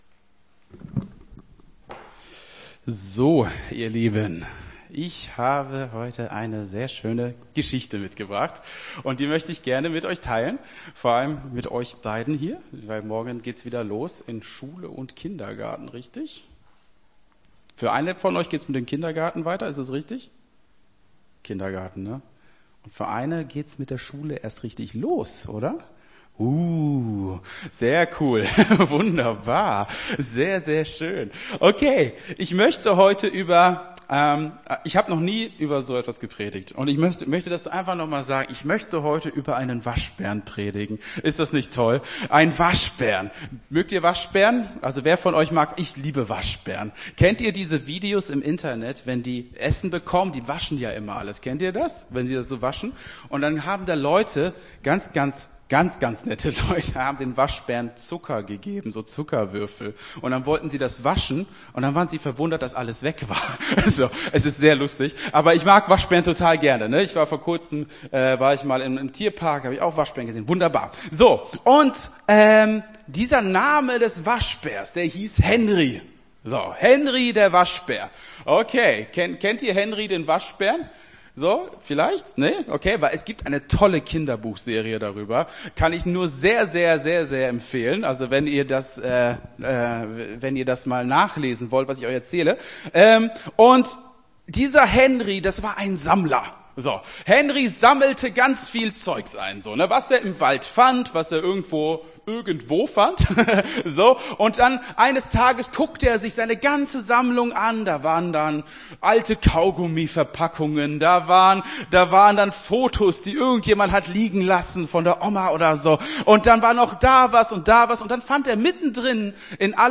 Gottesdienst 06.08.23 - FCG Hagen